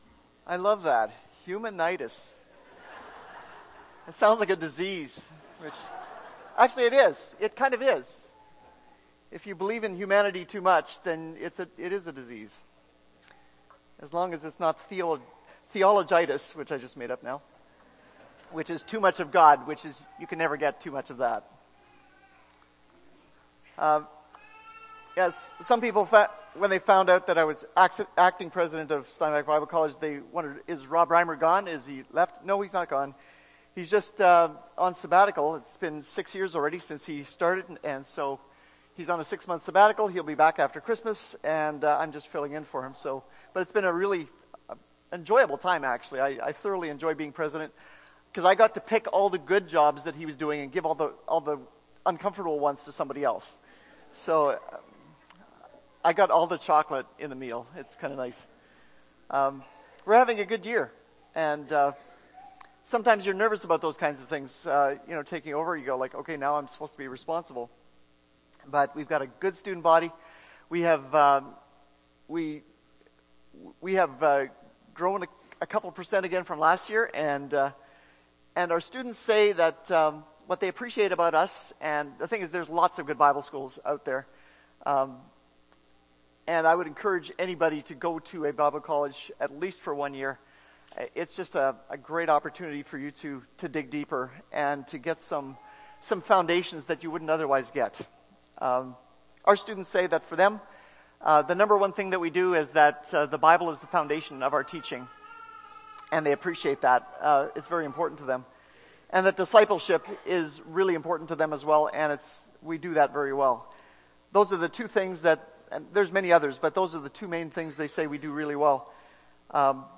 Oct. 13, 2013 – Sermon